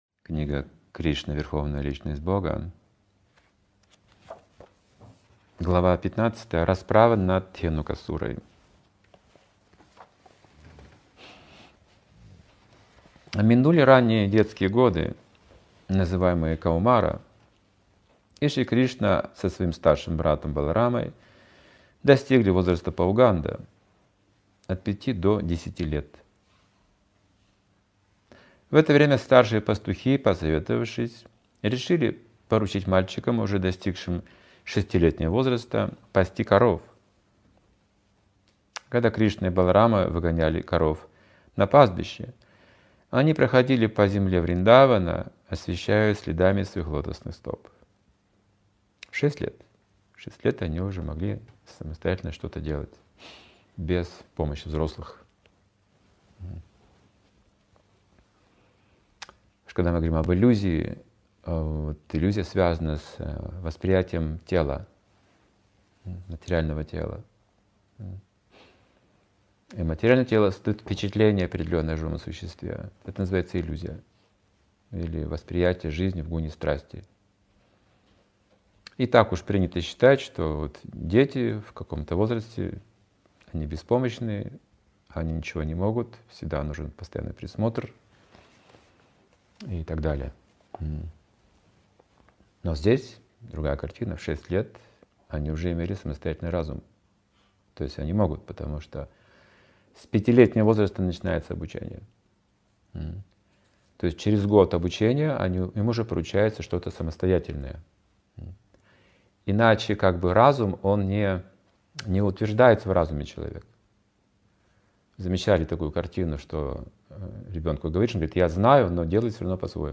Лекции и книги